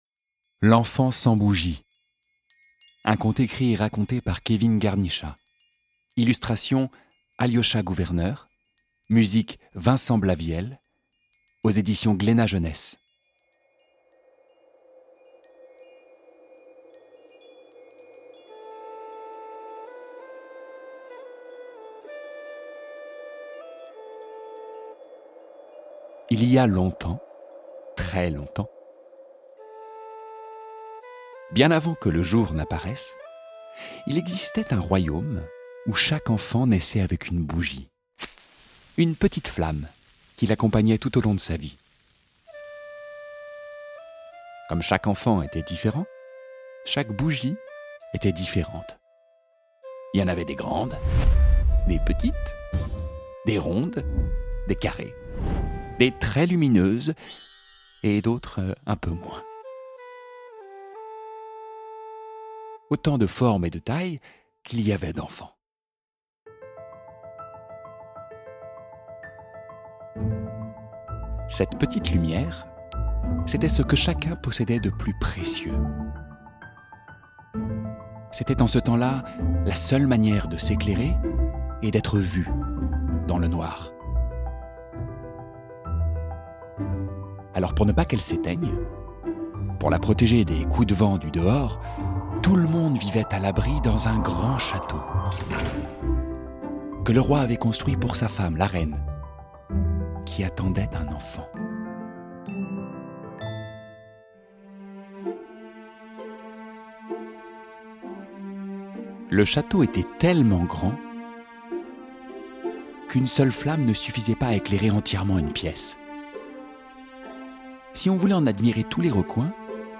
Un conte moderne entre le mythe de la caverne et Le Petit Prince, à lire et à écouter en version audio avec une création musicale originale !
*IH ou Interprétation Humaine signifie que des comédiennes et comédiens ont travaillé à l'enregistrement de ce livre audio, et qu'aucune voix n'a été enregistrée avec l'intelligence artificielle.